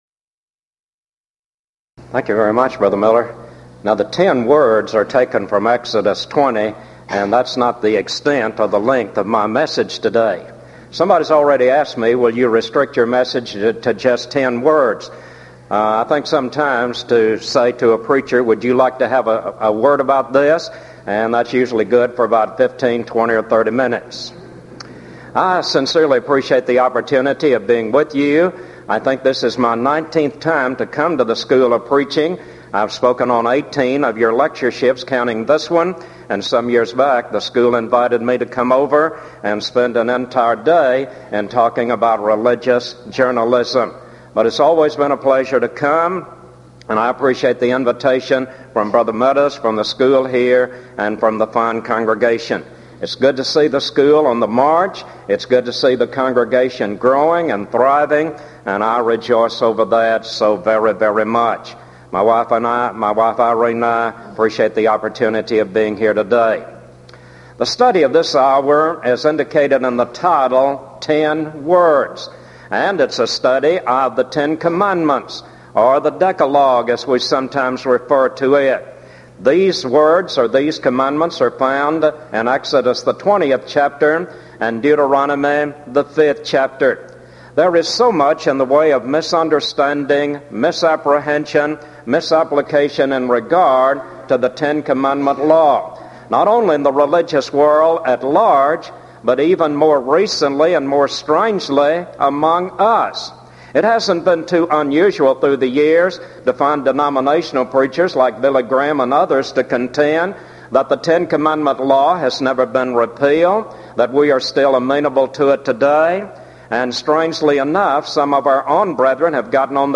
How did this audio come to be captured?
Event: 1997 East Tennessee School of Preaching Lectures